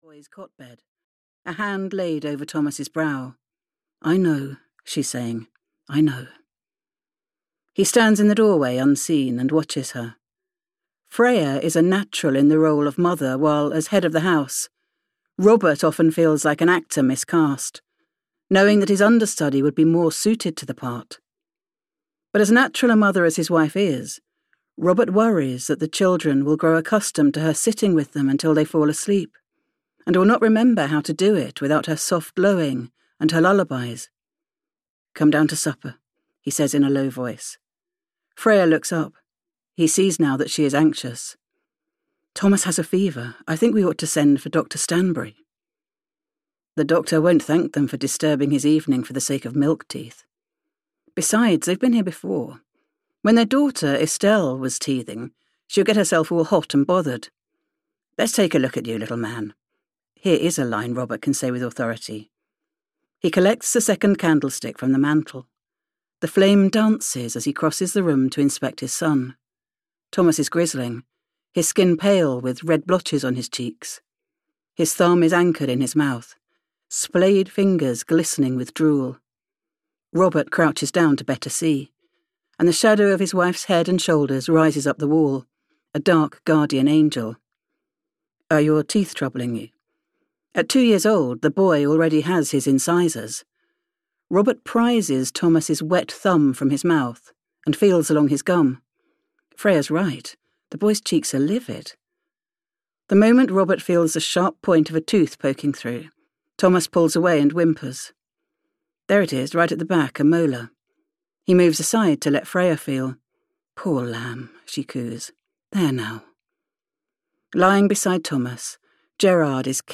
Small Eden (EN) audiokniha
Ukázka z knihy